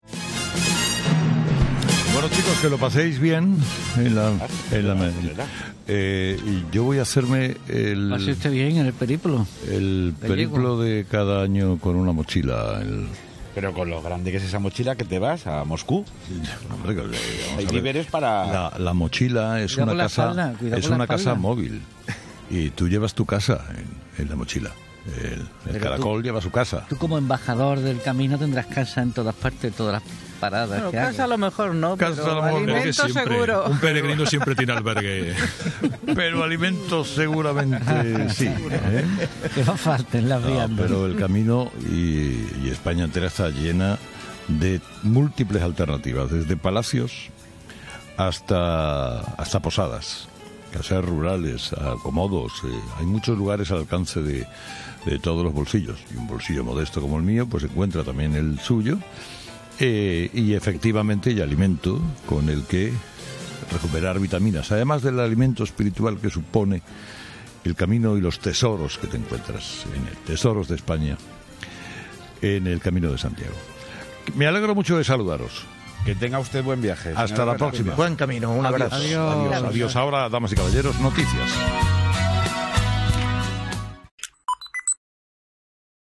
El director de 'Herrera en COPE' ha dado el pistoletazo de salida a su Camino de Santiago desde el set del programa especial de COPE en Sevilla